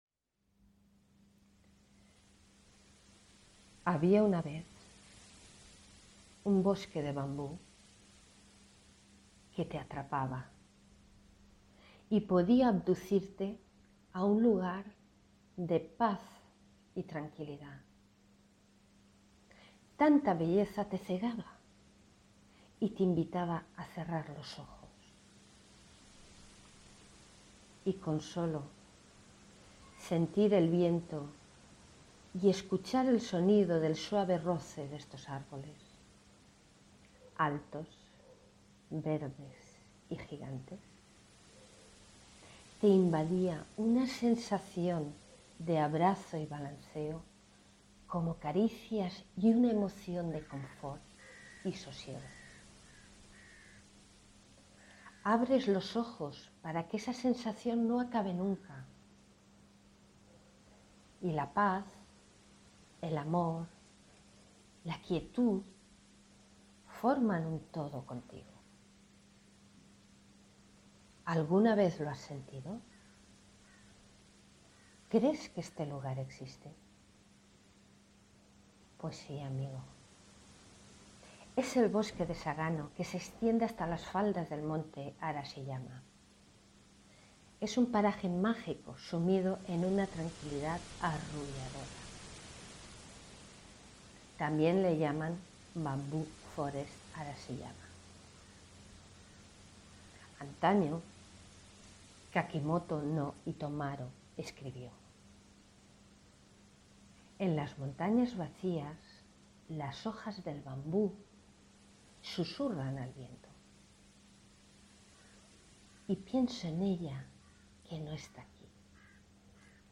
Música libre de derechos.